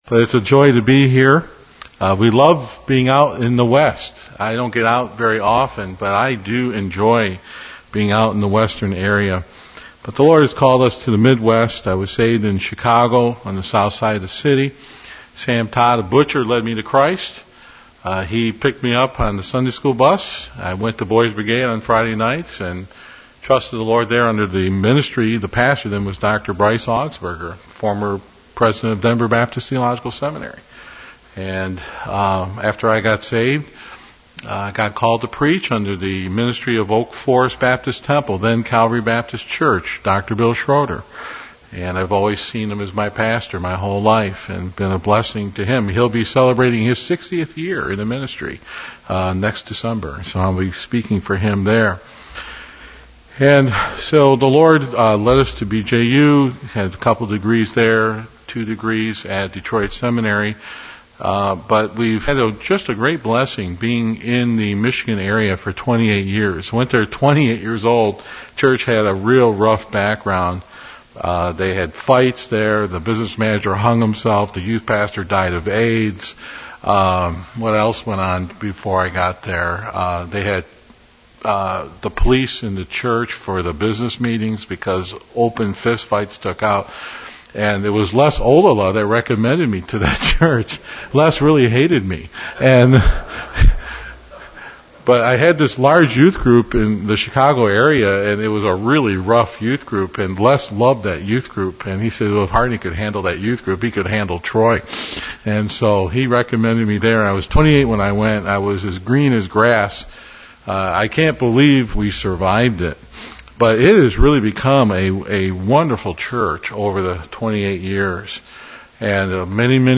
FBFI Northwest Regional Fellowship Mal 1.6-8